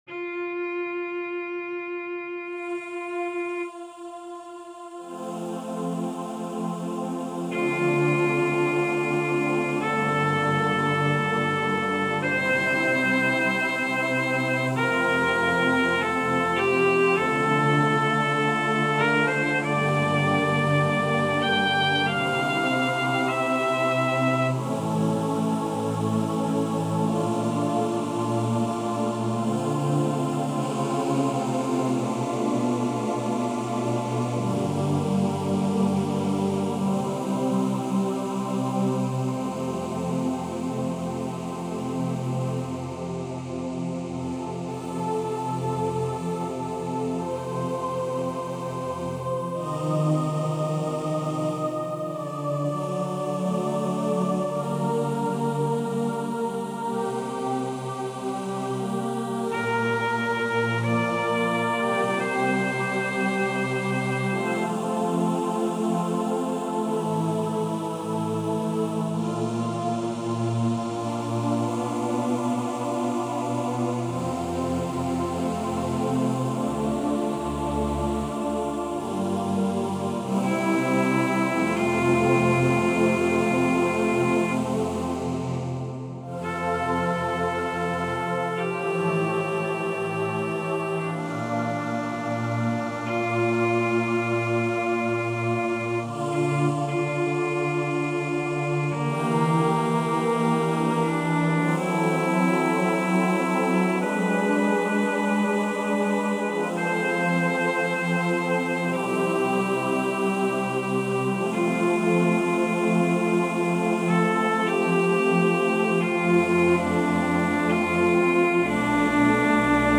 Jeg lekte meg litt med «planka» i kveld i samarbeid med Logicprogrammet på Mac’n, og dette ble resultatet.
Kor med cello
korstykke.mp3